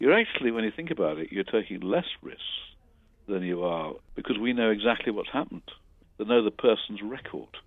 round-table discussion